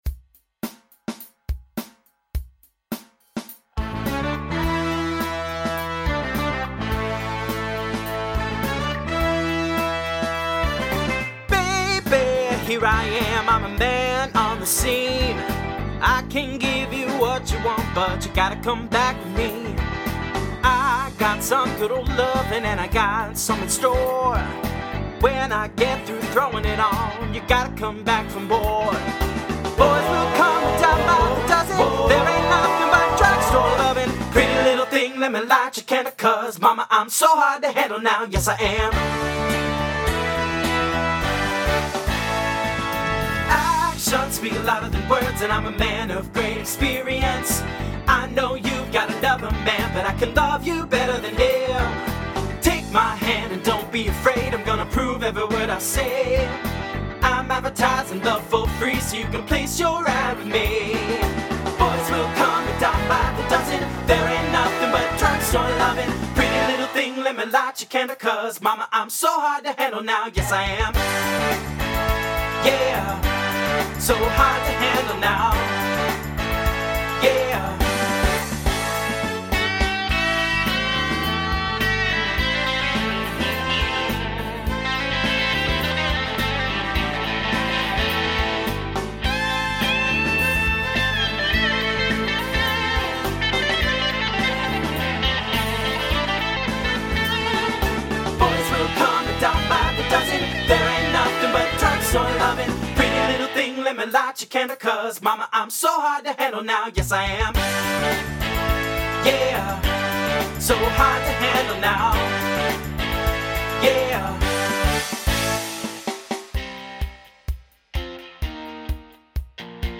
TTB/SSA